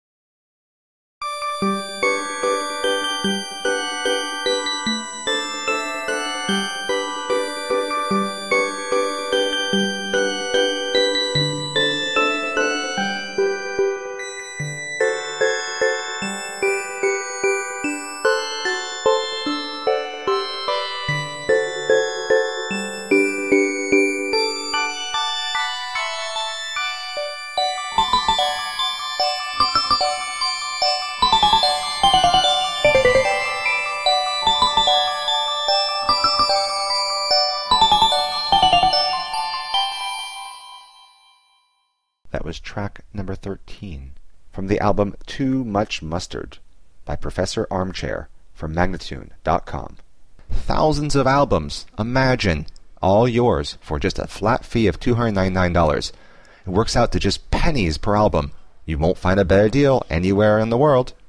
Demented 19th century children's music.
Circuses and carousels and calliopes, oh my!
Tagged as: Classical, Experimental, Experimental Electronic